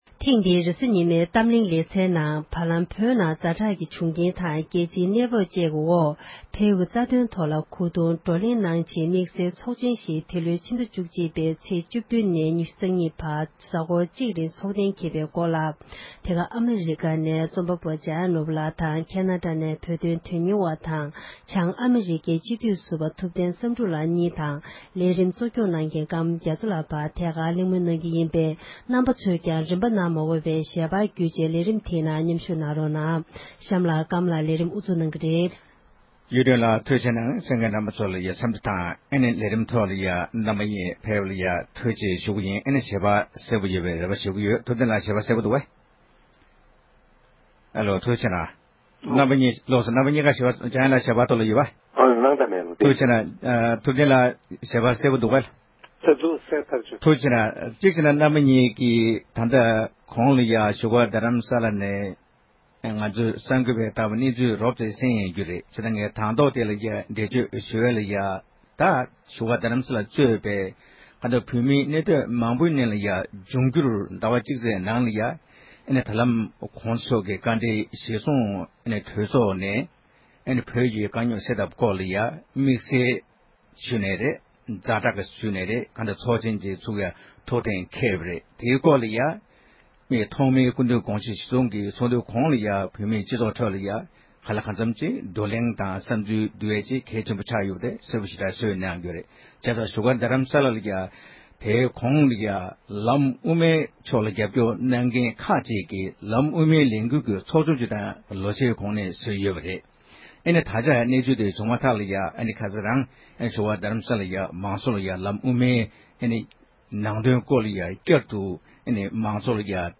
བགྲོ་གླེང་ཞུས་པར་གསན་རོགས་གནང་